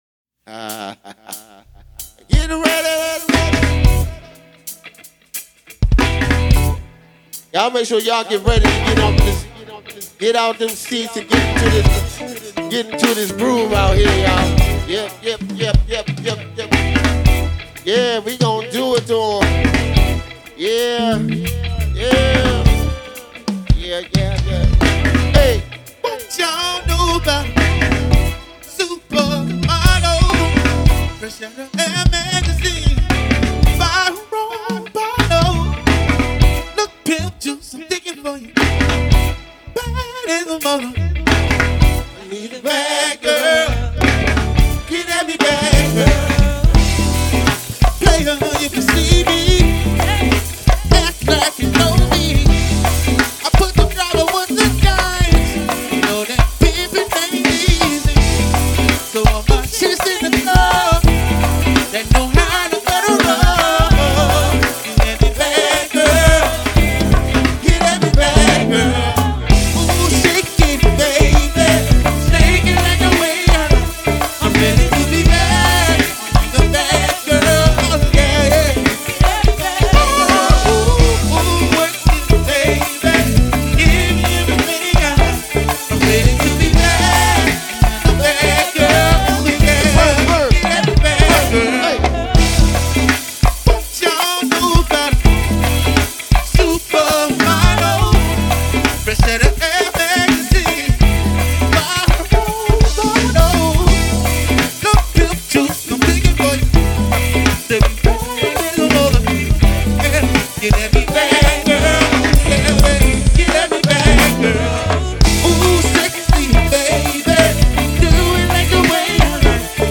PA RECORDINGS